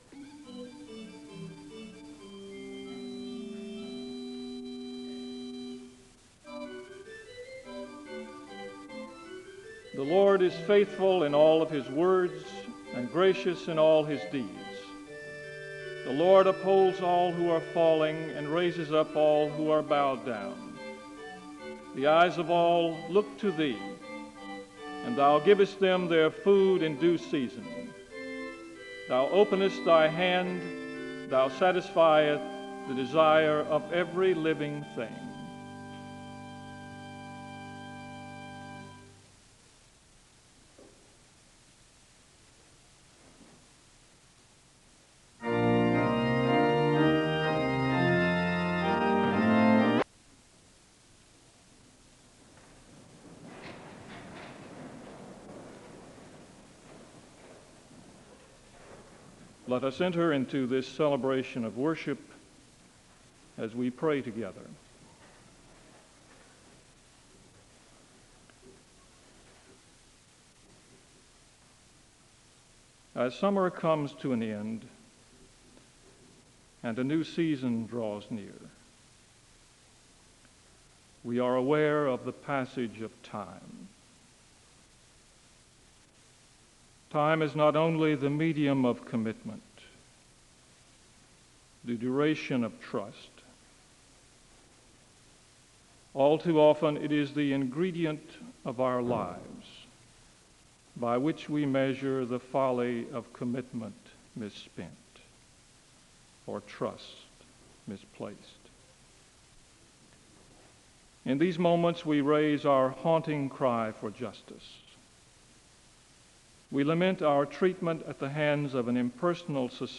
The service begins with a litany with the organ playing in the background, and the speaker gives a word of prayer (00:00-03:02). The choir sings a song of worship (03:03-06:04).